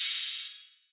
DDW2 OPEN HAT 2.wav